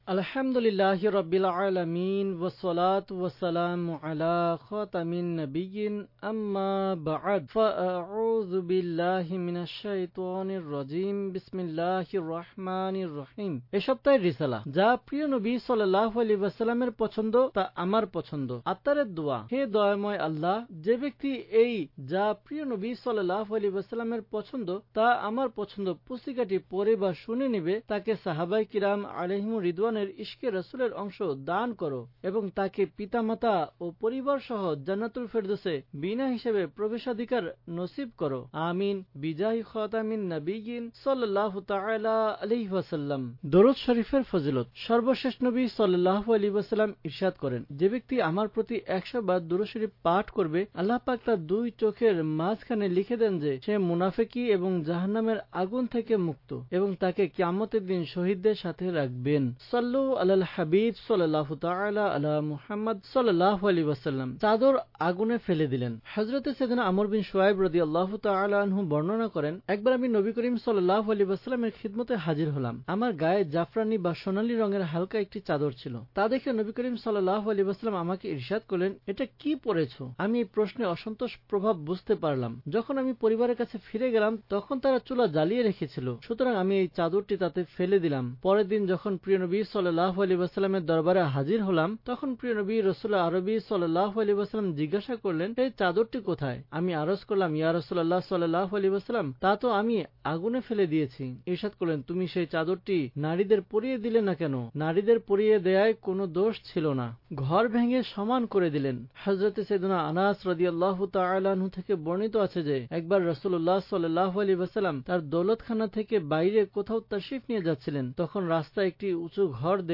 Audiobook - যা প্রিয় নবী ﷺ এর পছন্দ, তা আমার পছন্দ (Bangla)